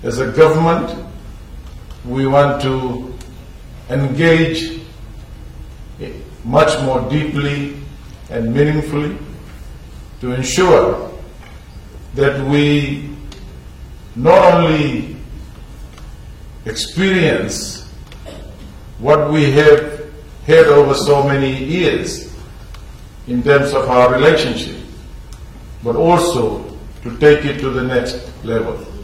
Speaking during the Youth Leadership Contest, Deputy Prime Minister Professor Biman Prasad says in the last nine months Fiji has accelerated its efforts to reestablish and deepen ties with India and the broader Pacific Islands region.
Deputy Prime Minister Professor Biman Prasad